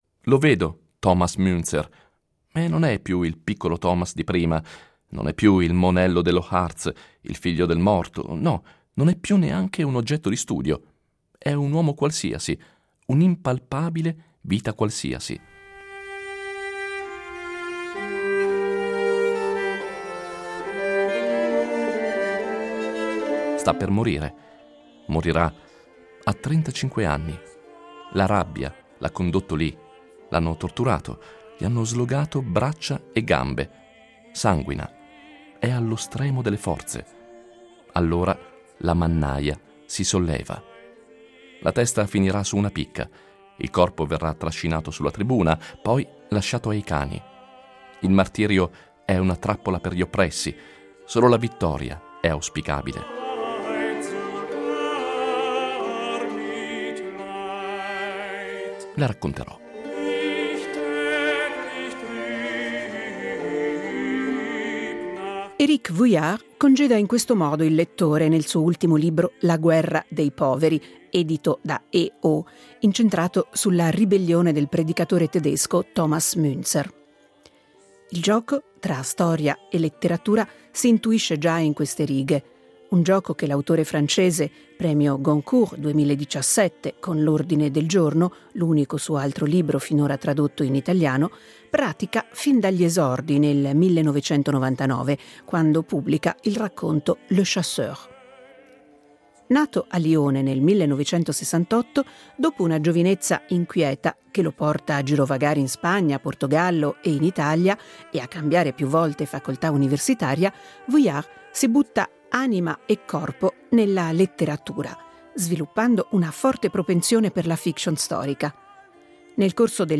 Incontro con Éric Vuillard
lo ha incontrato a Mantova durante Festivaletteratura.